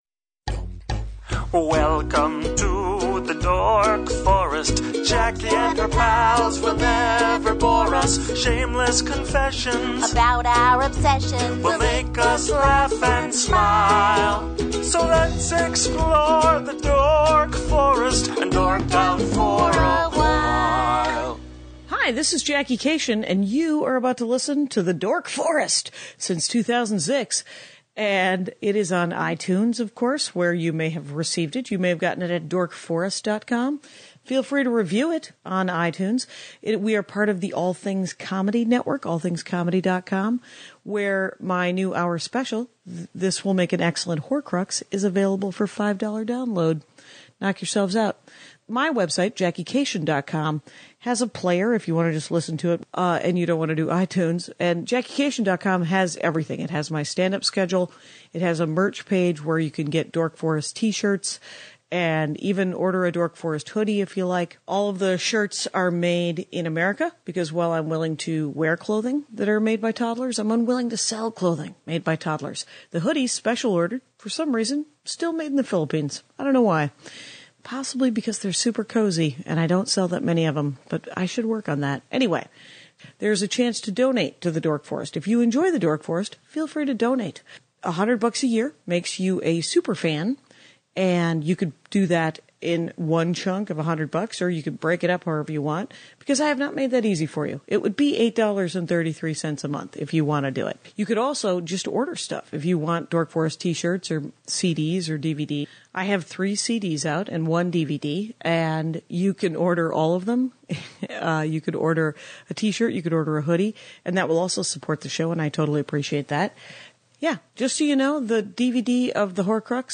She reads HER OWN AD.